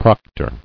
[proc·tor]